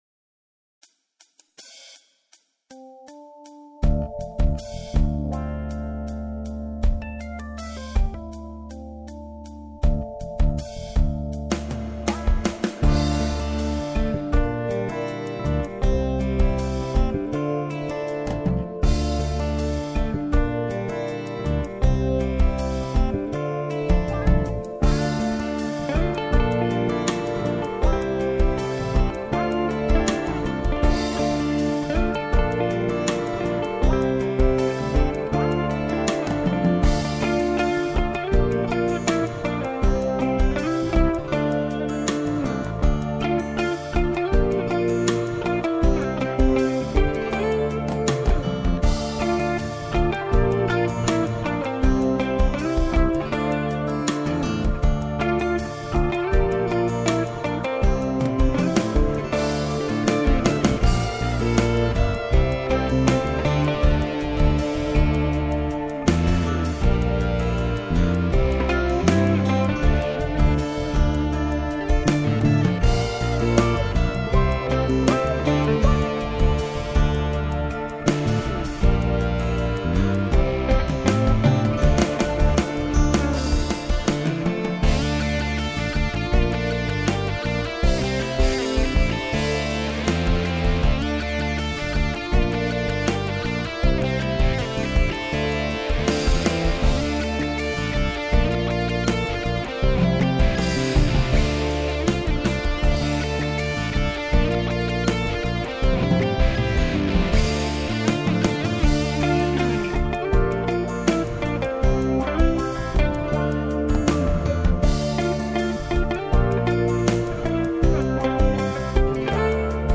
Год 2004 наверное, тогда вообще ни какого бфд, в общем стукач вообще не обработан и приторен особенно в середине темы, очень хотелось необычного замута в конце.